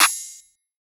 MZ Clap [Sevn #1].wav